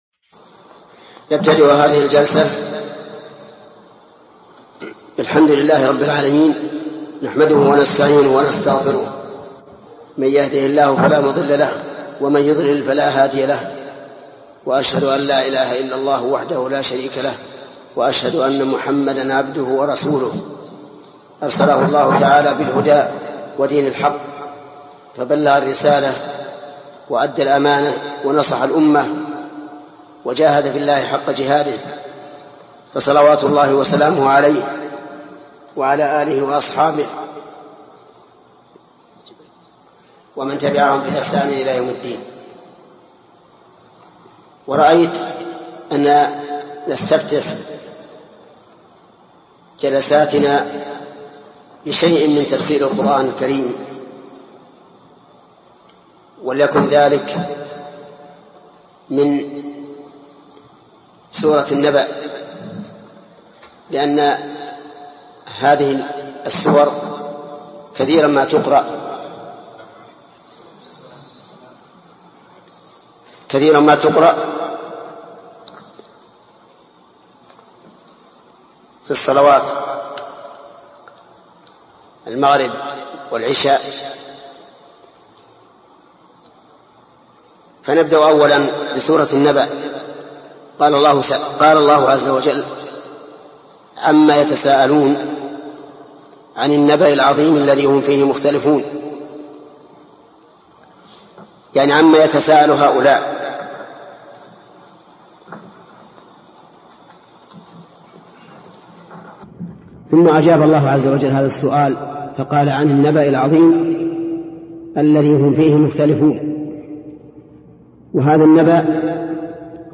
الدرس الثاني: تفسير سورة النبأ: من قوله: (تفسير سورة النبأ).، إلى: نهاية تفسير سورةالنبأ.